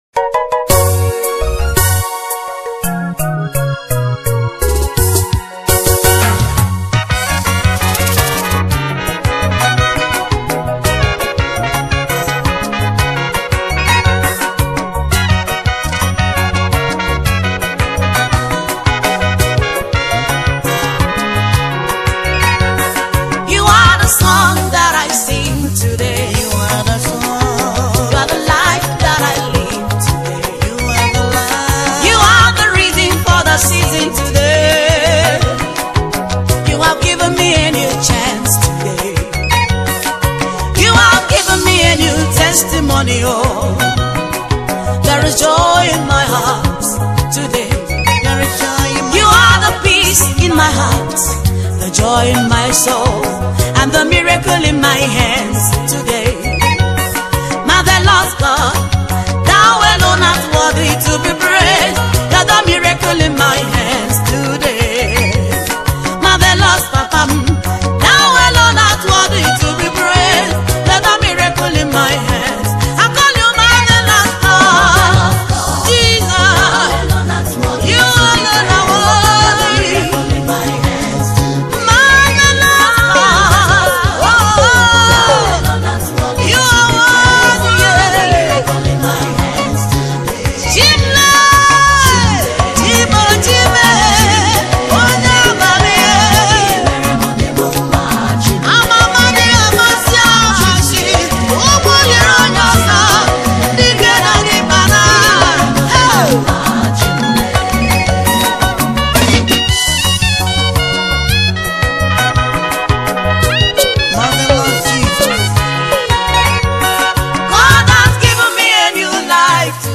Igbo Gospel music
worship single